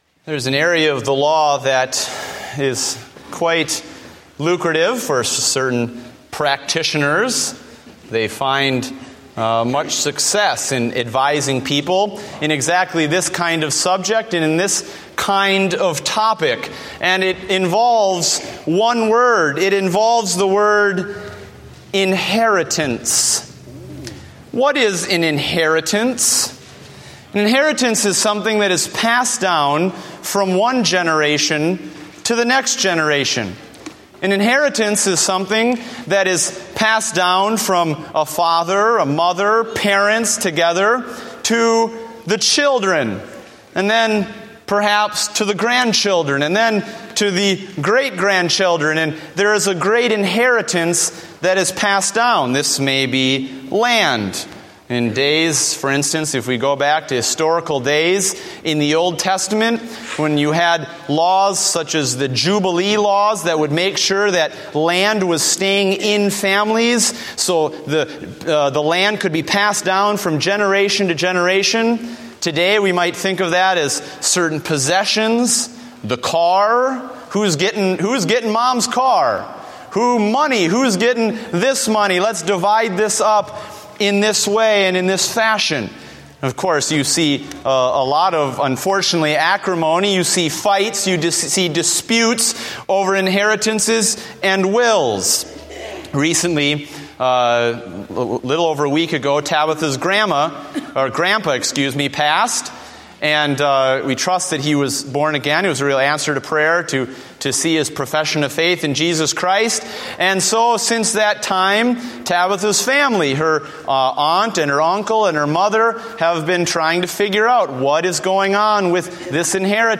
Date: November 2, 2014 (Morning Service)